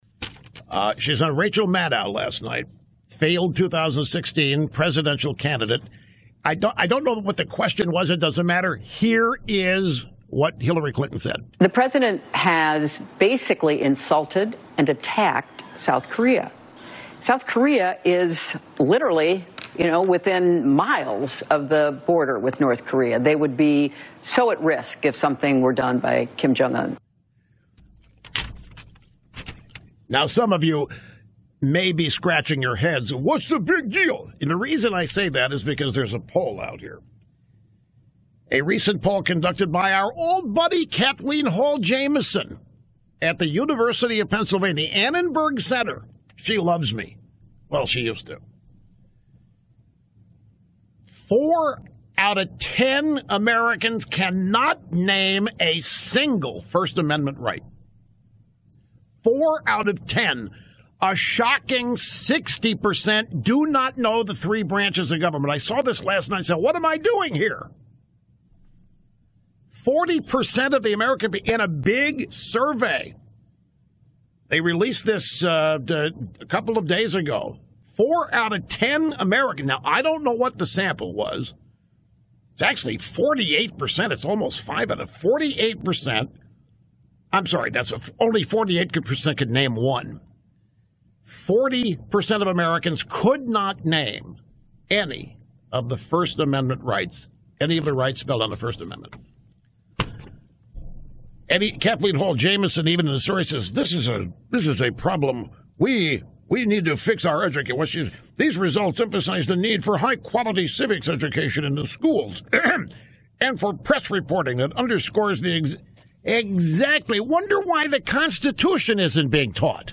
The survey was also discussed by Rush Limbaugh on his show on September 15.